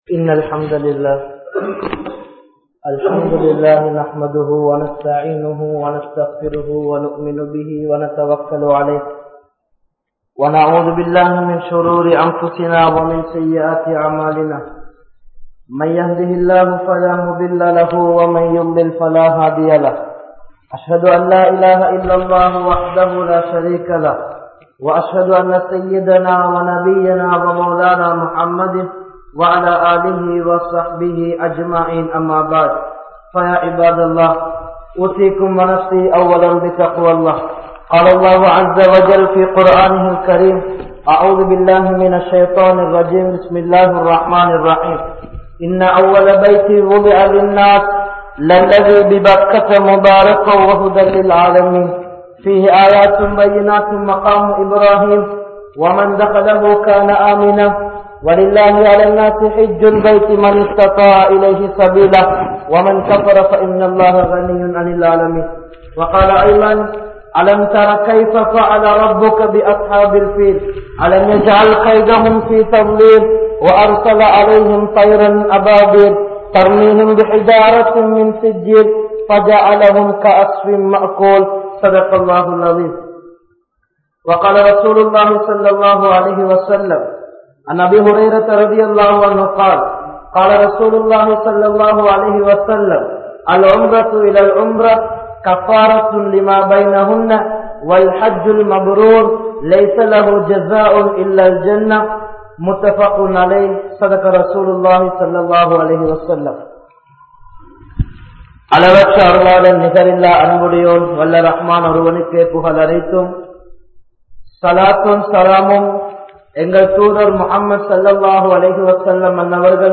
Hajj (ஹஜ்) | Audio Bayans | All Ceylon Muslim Youth Community | Addalaichenai